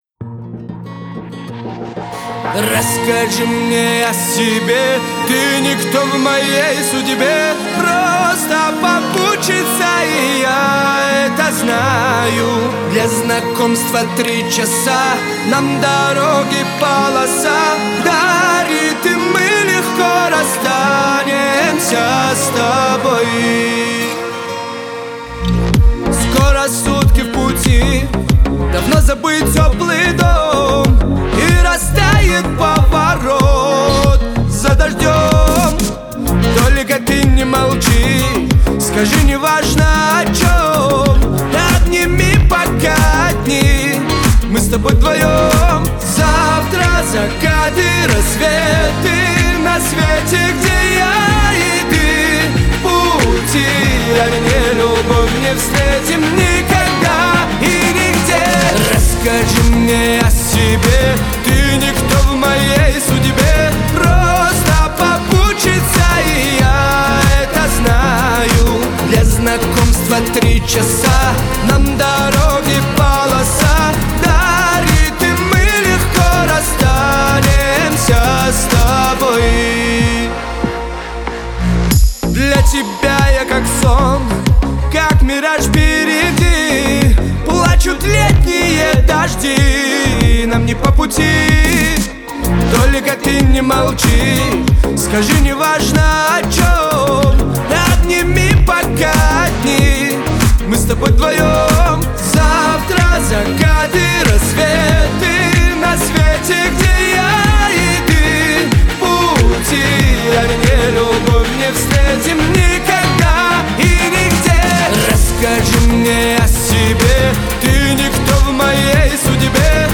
Кавер.